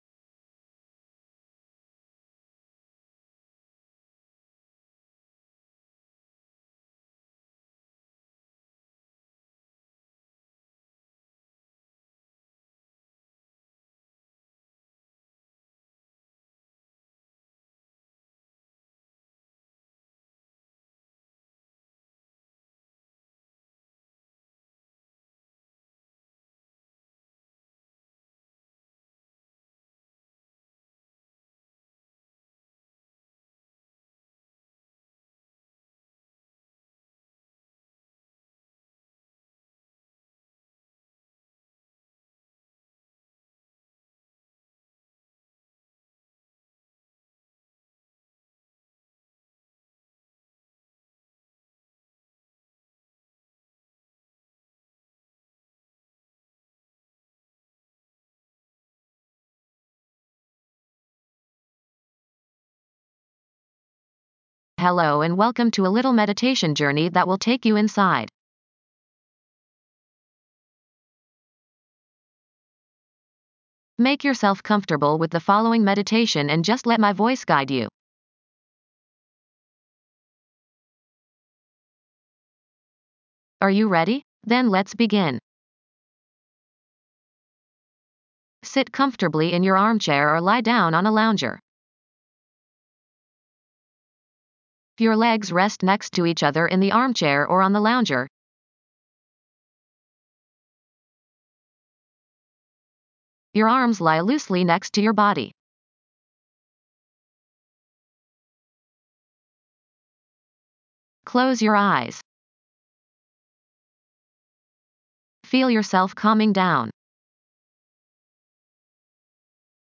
Hold your concentration on this / those spots while you let the music play over you. There is no affirmation here. In silence, continue to focus on the 22nd Chakra .